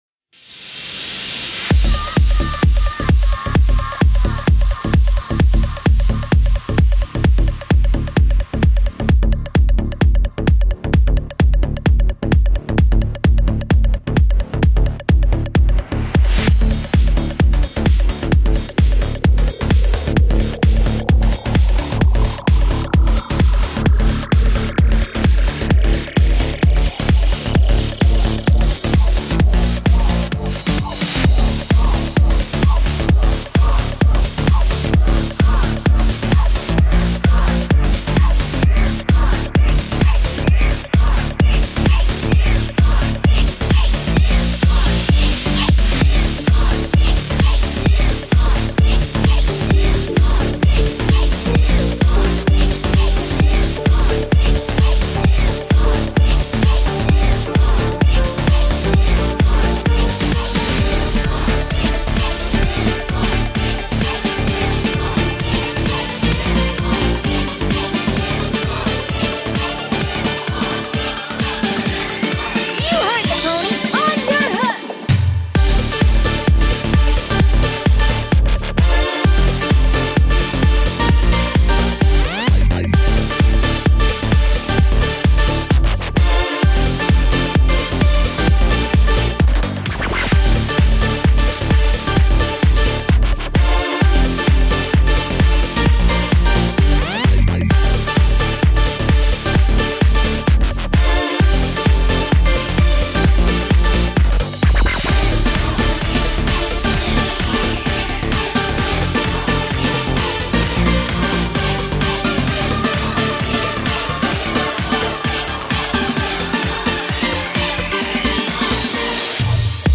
AND PRAISE THE COWBELL!
hihats, cymbals, supersaws, more wobbles
Saws, squares, more saws, blips, toms, drums, marimba